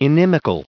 added pronounciation and merriam webster audio
438_inimical.ogg